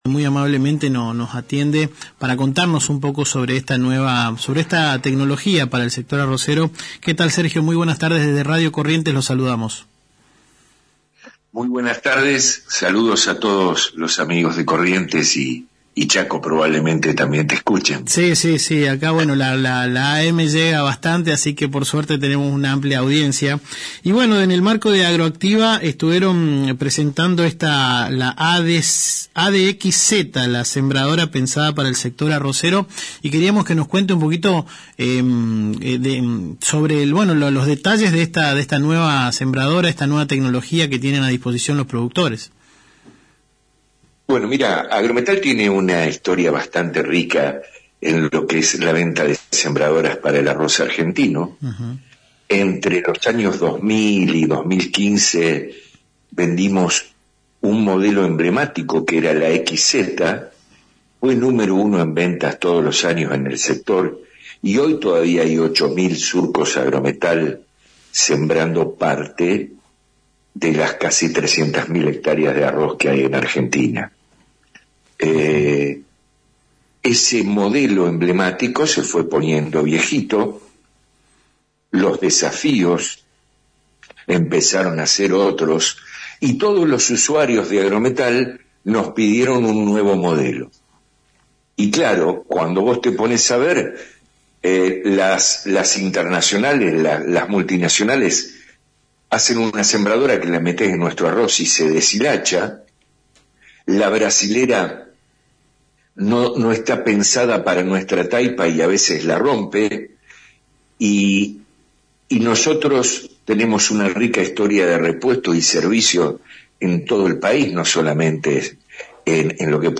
En este sentido en comunicación con Aires de Campo Radio